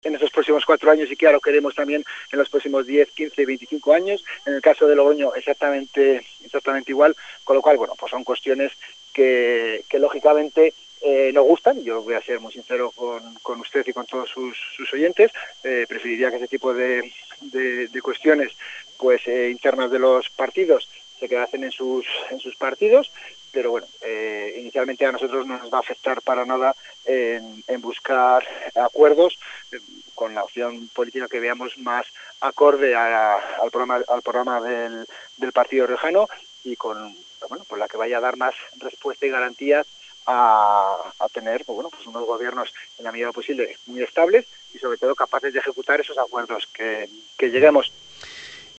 La edil electa de la formación morada lamenta lo ocurrido, en declaraciones a la CADENA SER en LA RIOJA, agradece a Leopoldo García (PR+) y Laura Rivado (PSOE) su comprensión y forma de reaccionar y anuncia que la acción de grabar y filtrar la grabación persigue dañar el posible pacto entre las tres formaciones políticas.